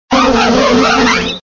Cri d'Apireine dans Pokémon Diamant et Perle.